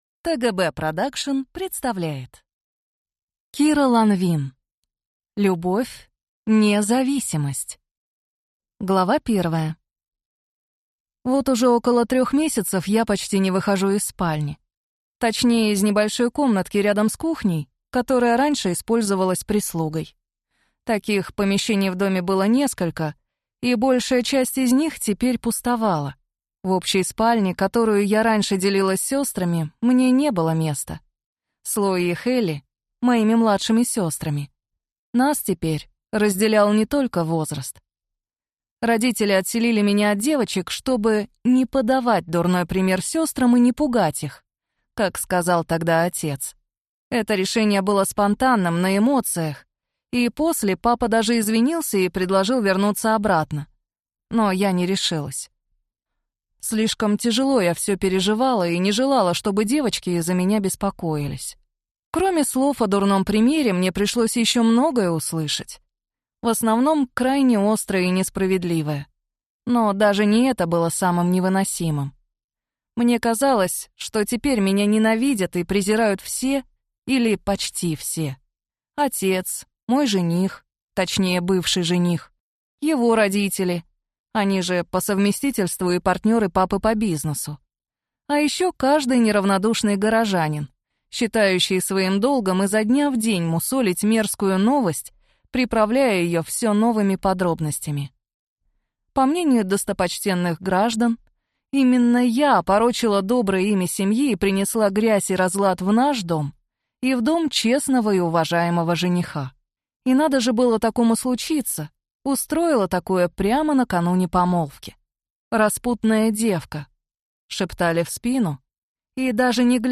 Аудиокнига Любовь (не) зависимость | Библиотека аудиокниг